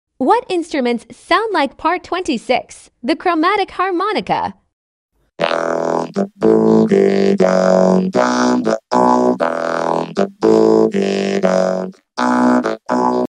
What Instruments Sound Like Part 26: The Chromatic Harmonica